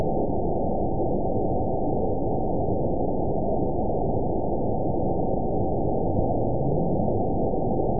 event 922647 date 02/23/25 time 23:00:44 GMT (2 months, 1 week ago) score 9.56 location TSS-AB01 detected by nrw target species NRW annotations +NRW Spectrogram: Frequency (kHz) vs. Time (s) audio not available .wav